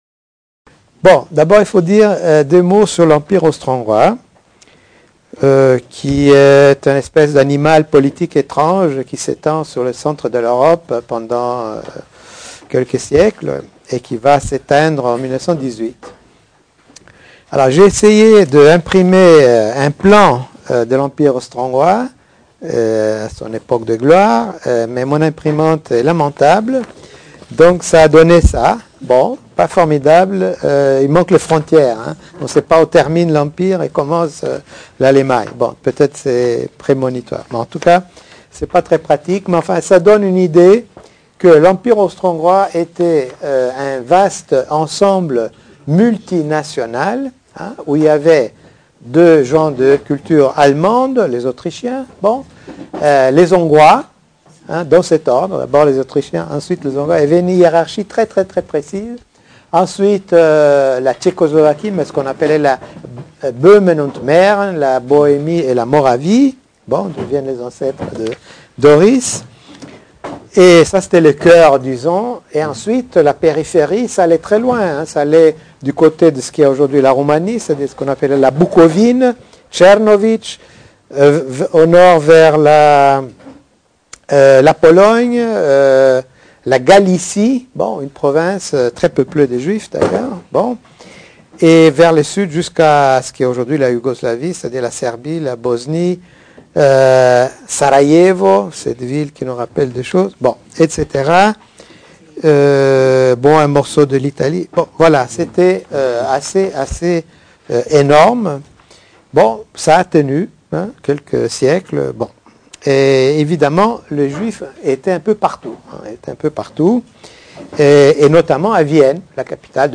Get your own - Open publication Écouter la conférence (au format mp3) Conférence de Michael Löwy à Paris en octobre 2008 00:00 / 00:00 Télécharger ici (Clic droit « Enregistrer sous »).